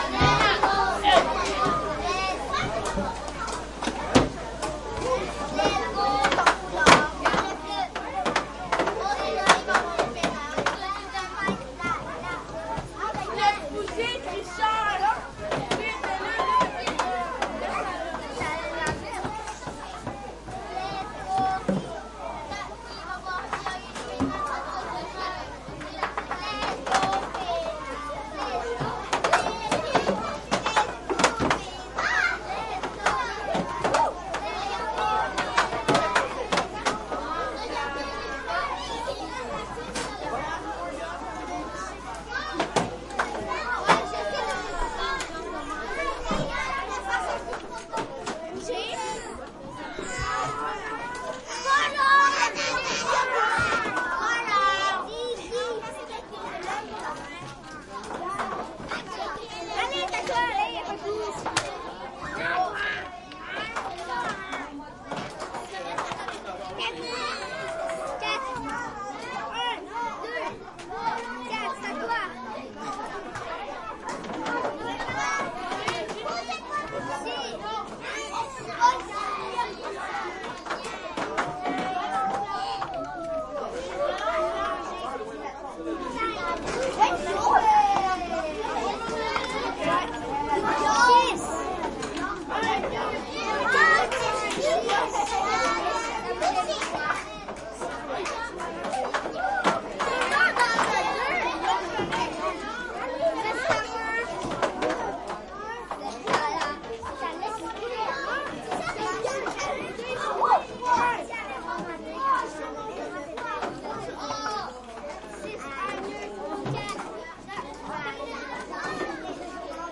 蒙特利尔 " 人群中 小学或幼儿园的孩子们 法语儿童 魁北克午餐时间 孩子们活泼好动的游戏2
描述：人群int小学或幼儿园儿童法国enfants quebecois午餐时间孩子活泼活跃的游戏2
Tag: 儿童 儿童组织 魁北克 小学 活泼 好动 幼儿园 法国 INT 人群 孩子们 学校 沃拉